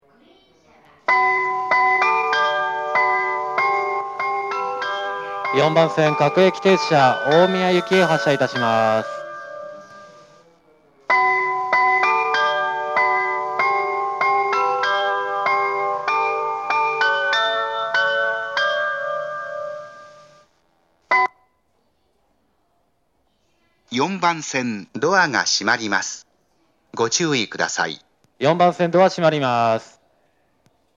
発車メロディー
(ATOS更新前）   2.1コーラスです!